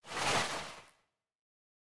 sounds / mob / breeze / slide3.ogg
slide3.ogg